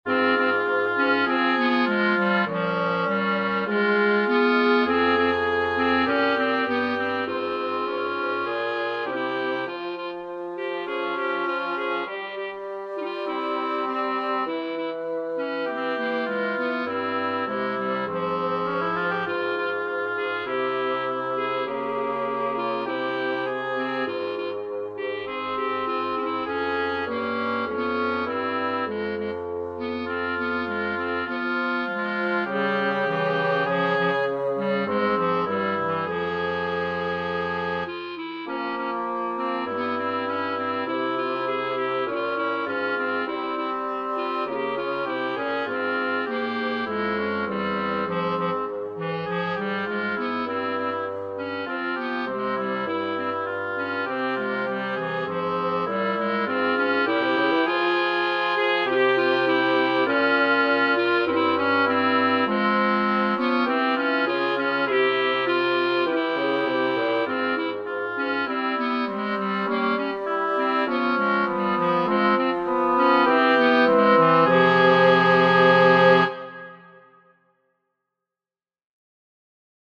Oboe, Clarinet, Bassoon
Then adapted for this mixed trio.